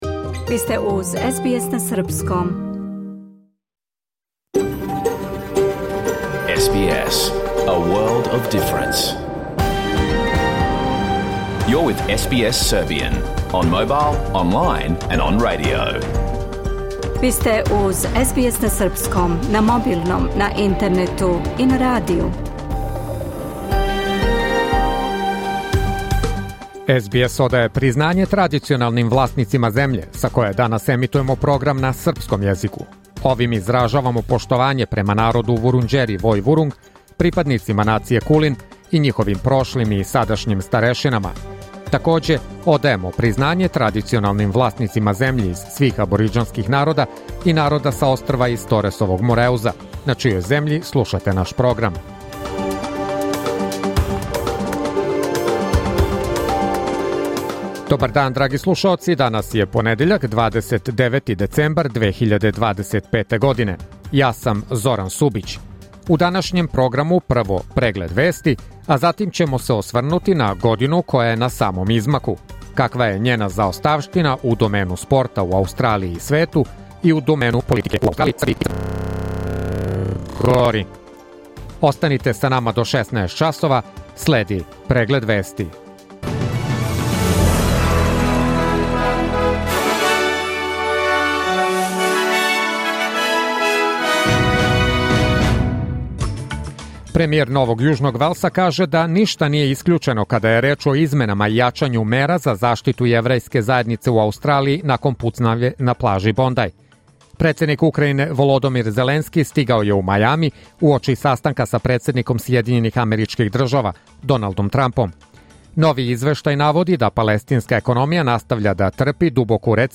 Програм емитован уживо 29. децембра 2025. године